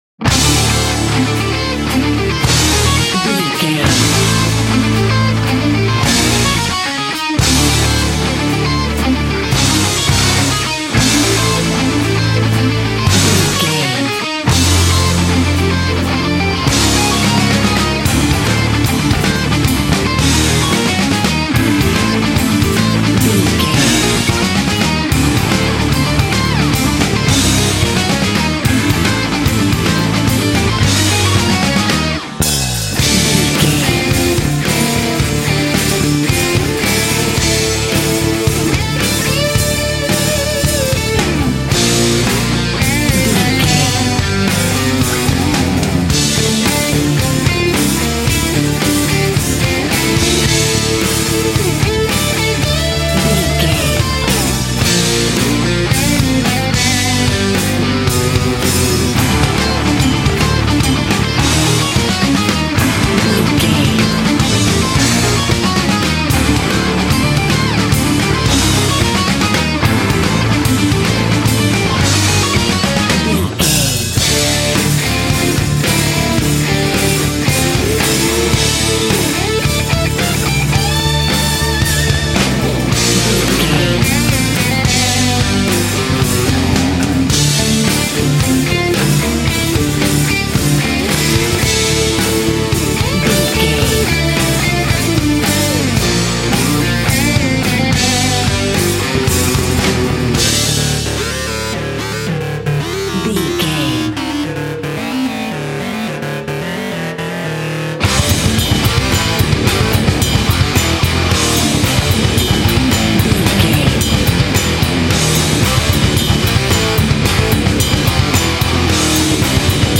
Aeolian/Minor
drums
electric guitar
bass guitar
hard rock
lead guitar
aggressive
energetic
intense
nu metal
alternative metal